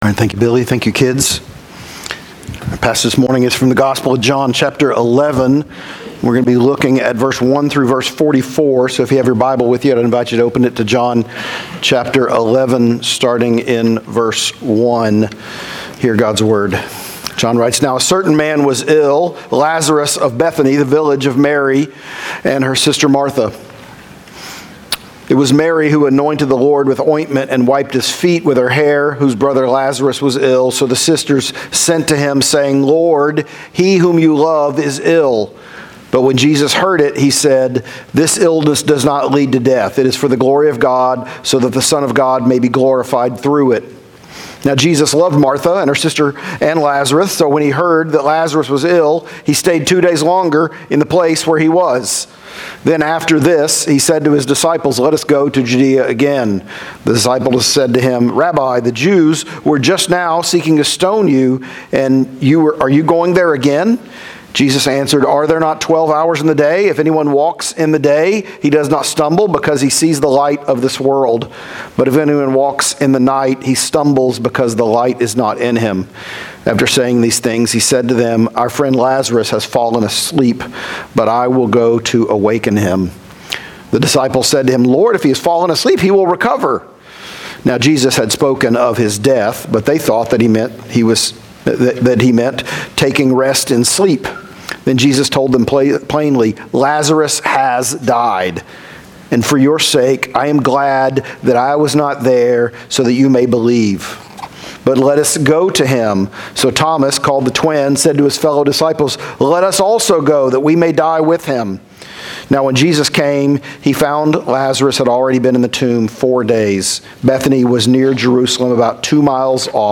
Sermons | Hope Church PCA